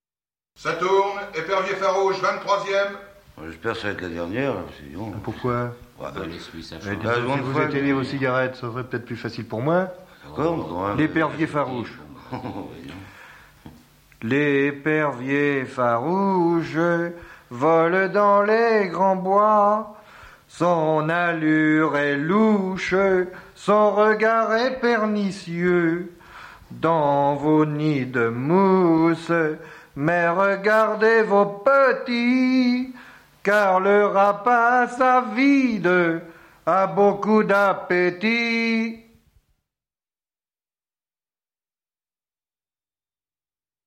(folklore)